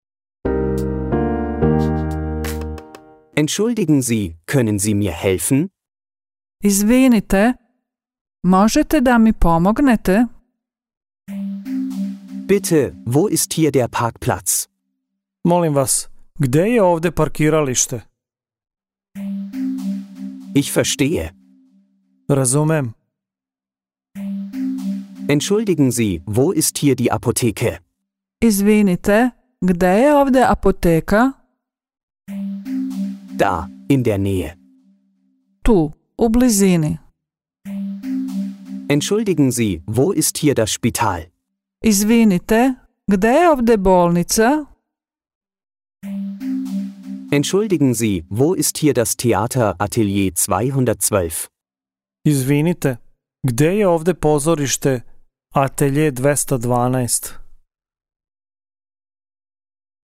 Hörbuch in serbischer Sprache mit deutscher Übersetzung
Die Sätze werden zunächst auf Deutsch präsentiert, gefolgt von der serbischen Version und einer einladenden Pause, um den Satz auf Serbisch zu wiederholen.
Hoerprobe-Serbisch-Einfache-Saetze-1-mit-DE-Uebersetzung.mp3